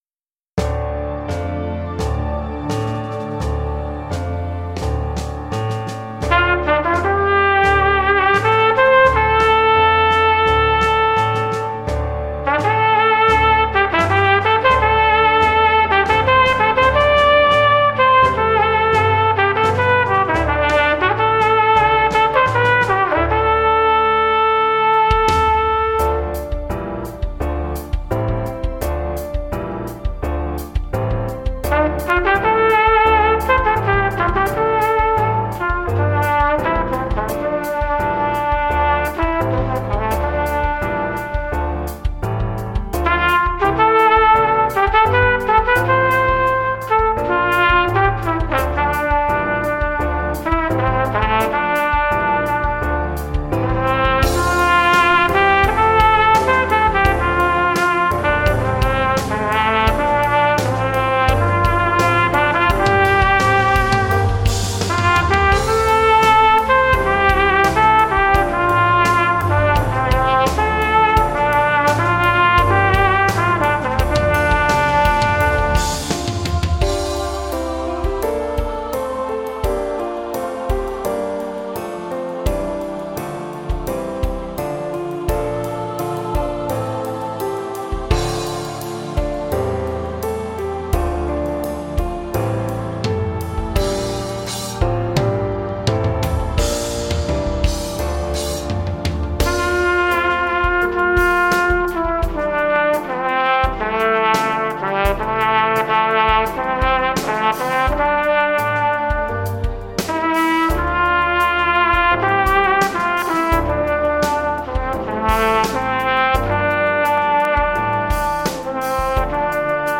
TROMBA SOLO
TROMBA SOLO • ACCOMPAGNAMENTO BASE MP3
Concerto